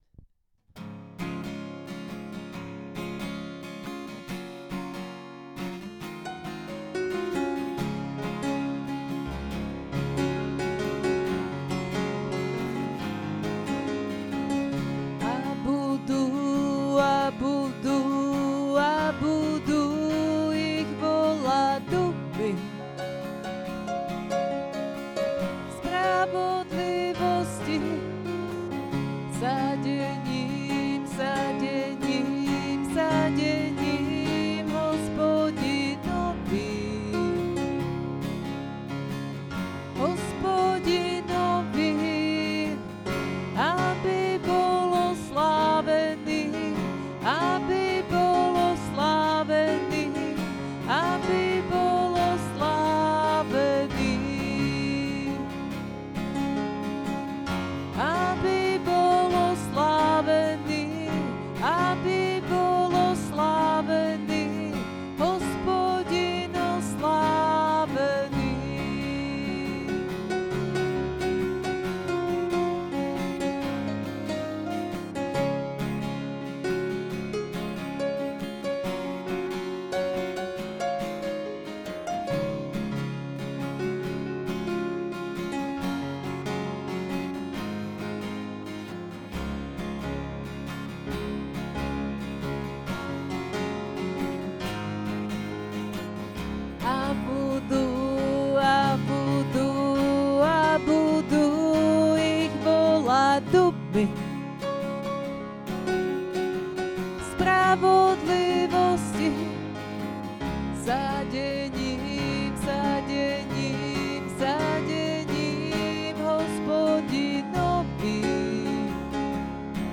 Stretnutie detí v Bohdanovciach
zhudobnený verš